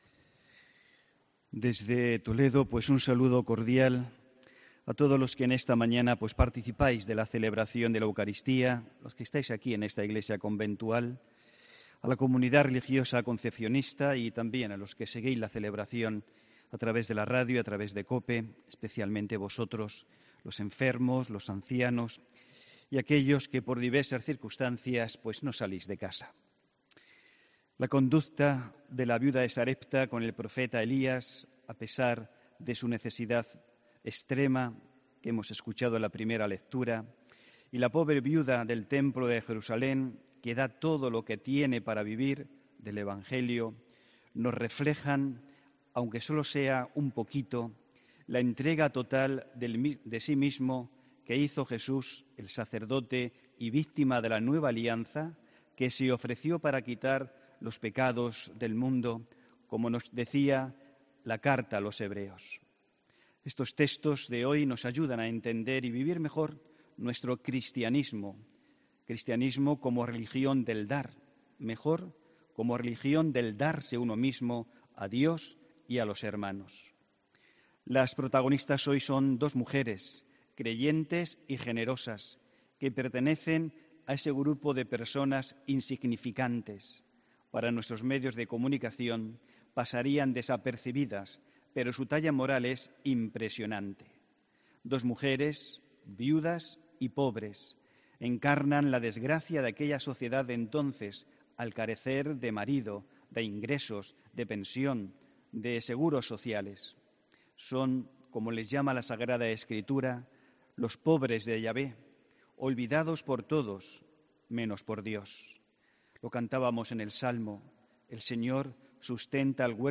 HOMILÍA 11 DE NOVIEMBRE